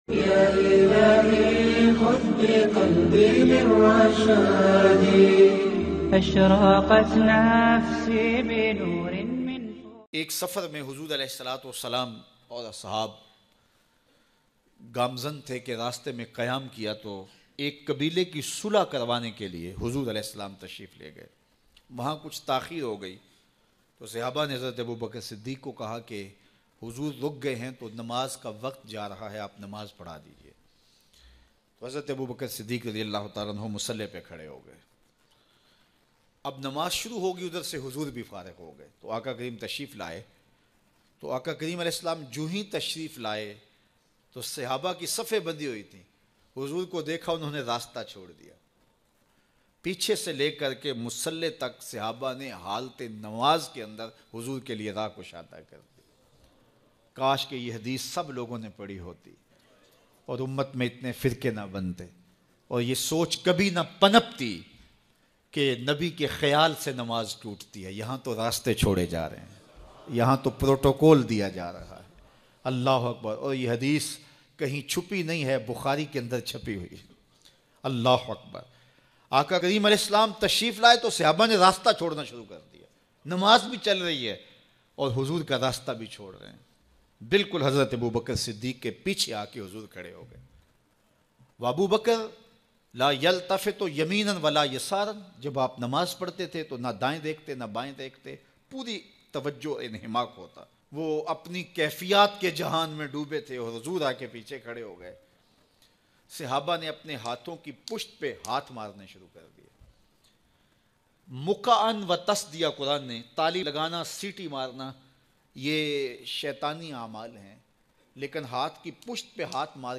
Kia Namaz Ma Khayal Any Se Namaz Toot Jati Hai bayan mp3